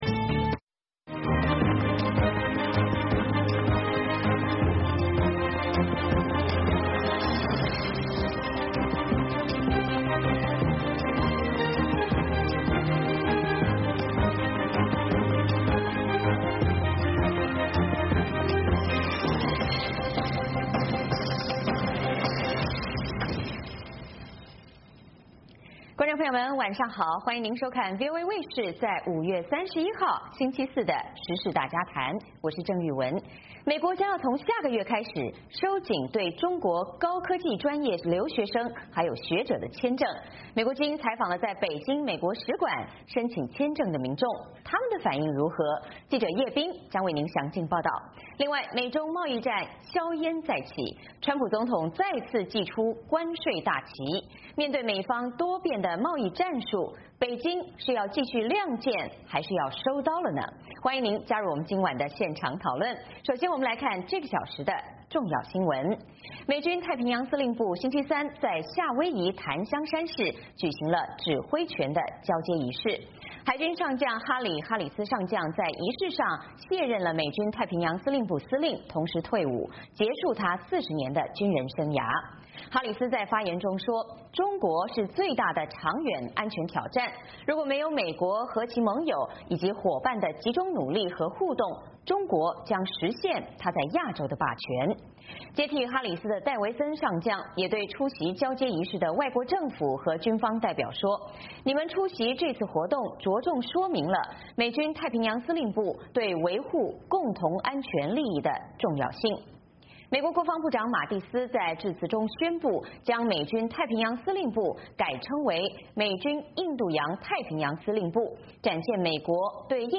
美中贸易斗争，下一步会如何演变？时事大家谈邀请专家与听众观众及网友朋友们一同来探讨这些问题。